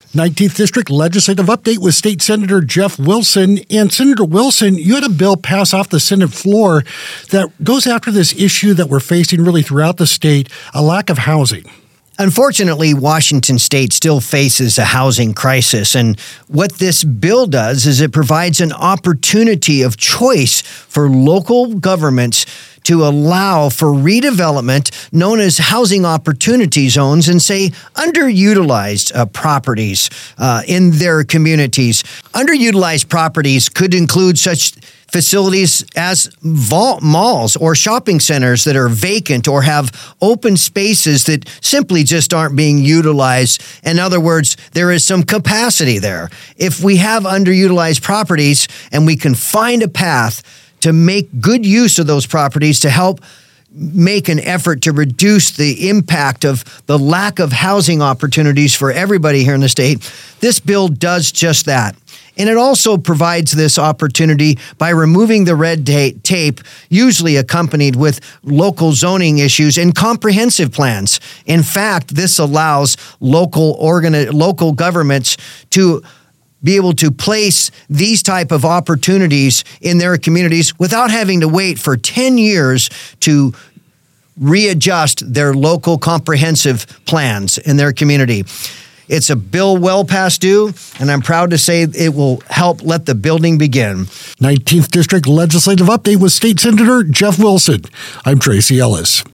Sen. Jeff Wilson presents his bill to address the housing shortage in Washington.